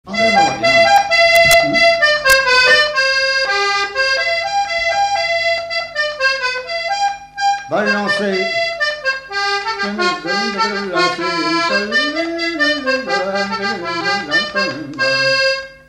Mareuil-sur-Lay
Résumé instrumental
gestuel : danse
Pièce musicale inédite